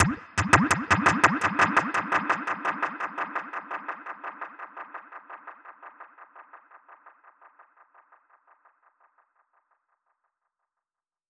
DPFX_PercHit_B_85-02.wav